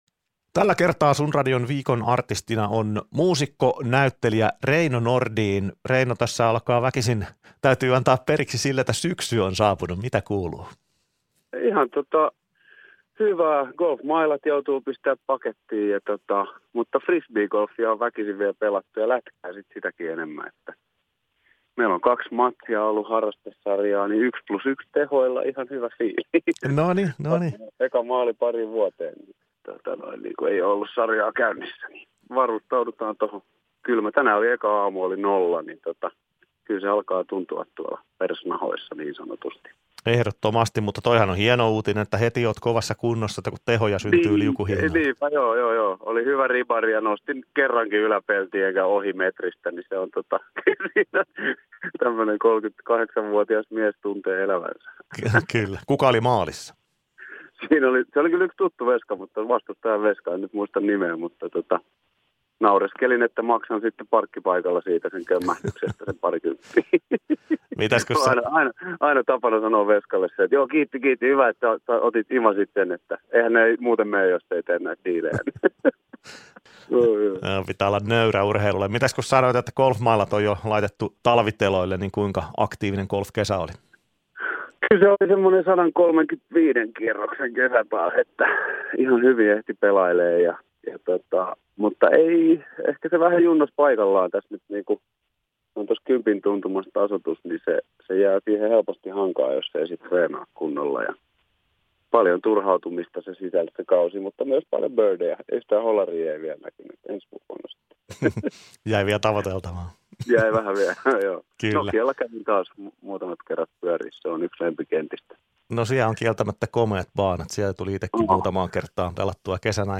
Viikon Artisti kertoili hyväntuulisessa haastattelussa myös muista harrastuksistaan mm. golfin ja jääkiekon parissa. Lisäksi hän muisteli elokuvien tekoa jo lapsesta alkaen.